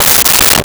Toilet Paper Dispenser 05
Toilet Paper Dispenser 05.wav